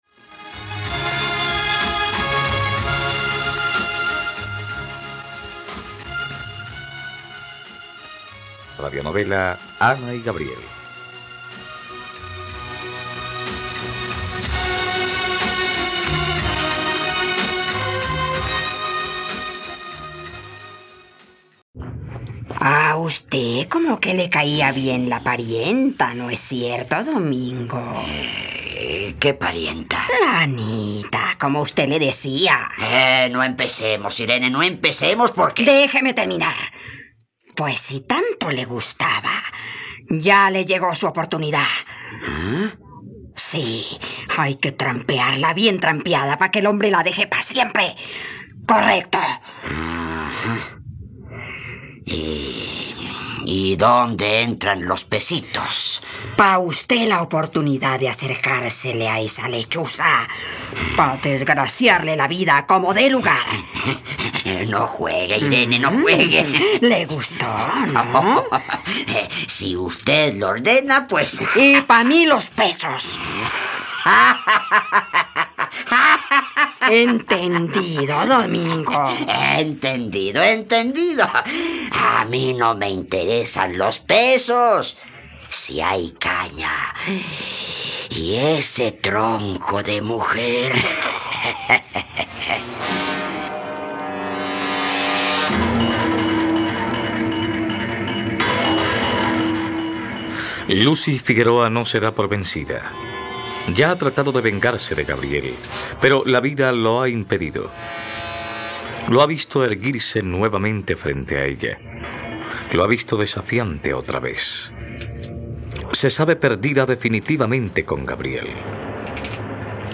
..Radionovela. Escucha ahora el capítulo 107 de la historia de amor de Ana y Gabriel en la plataforma de streaming de los colombianos: RTVCPlay.